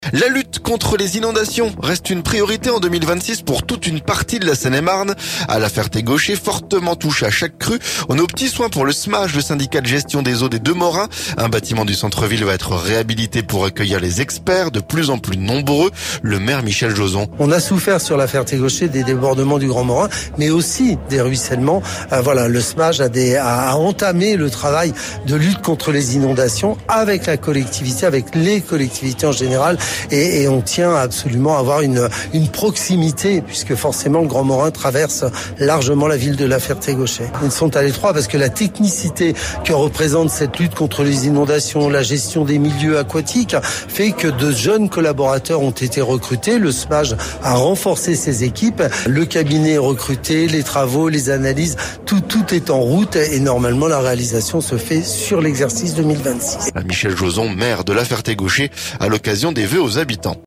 Un bâtiment du centre-ville va être réhabilité pour accueillir les experts, de plus en plus nombreux. Le maire, Michel Jozon.